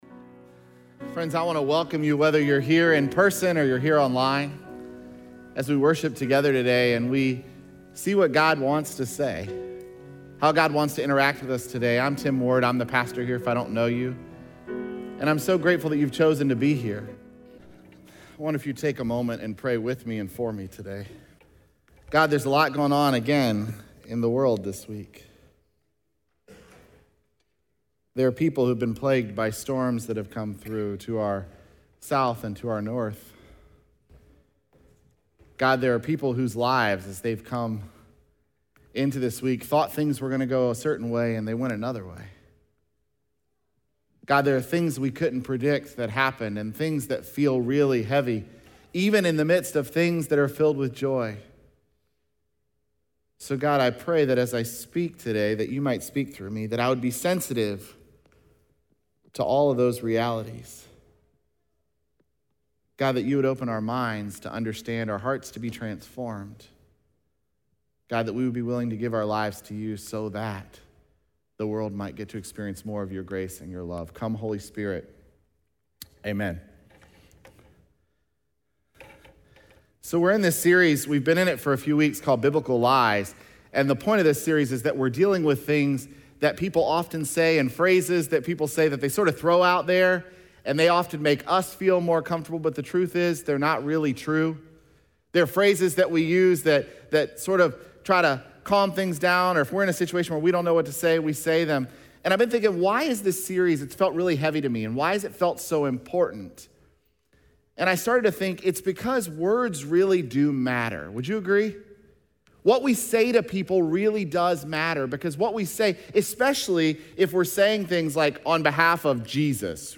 Crossroads Nova Sermons